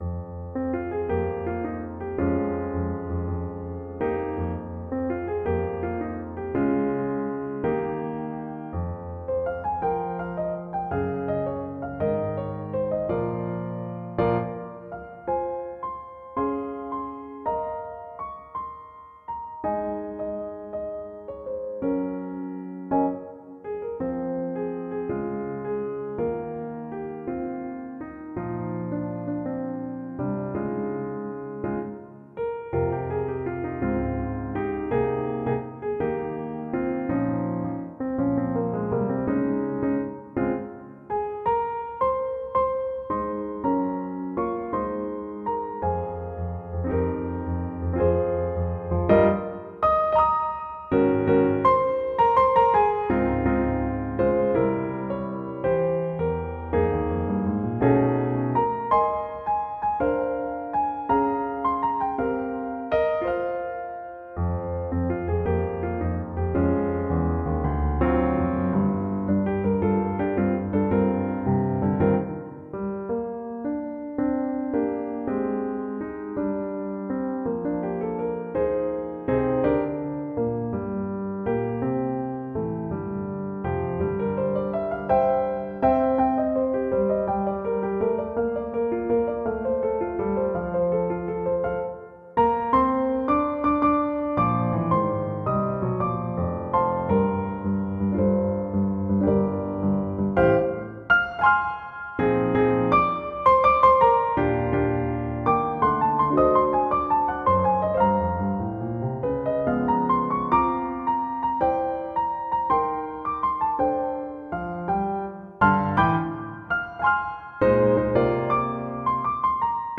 Composer: Traditional
Voicing: Piano